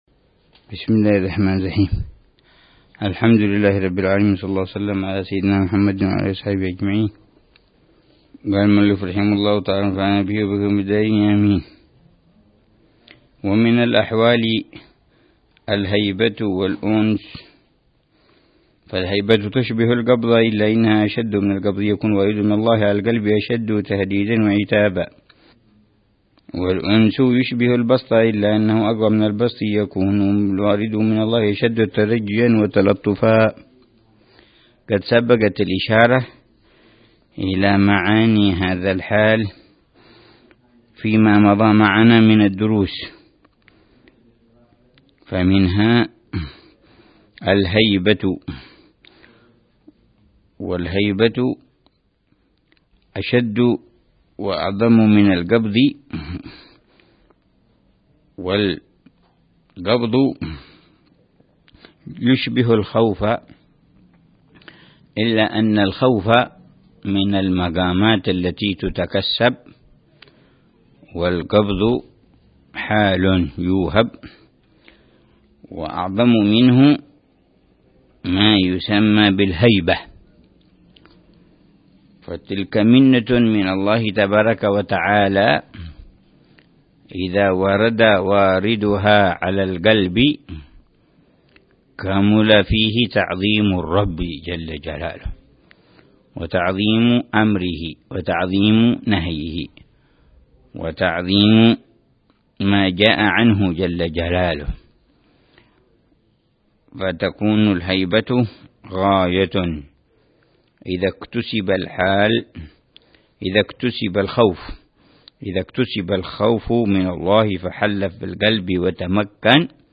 درس أسبوعي يلقيه الحبيب عمر بن حفيظ في كتاب الكبريت الأحمر للإمام عبد الله بن أبي بكر العيدروس يتحدث عن مسائل مهمة في تزكية النفس وإصلاح القلب